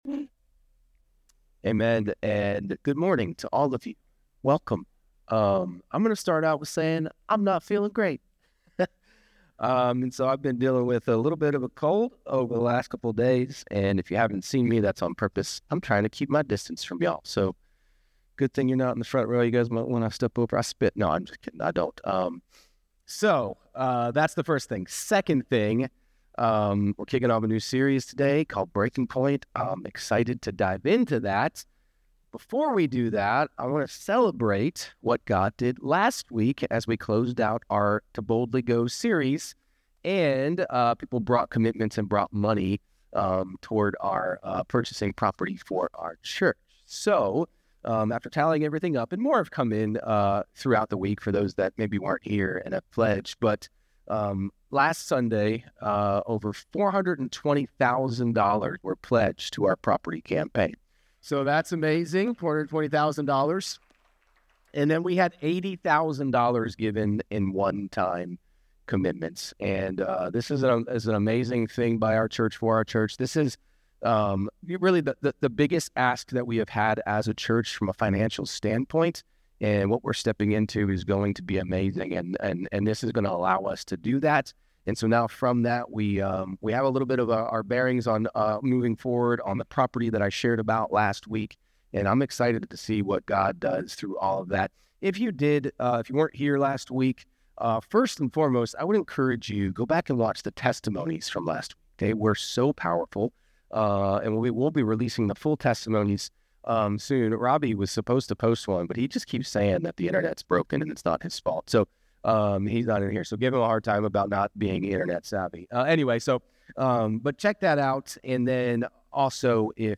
Sermons | Kairos Church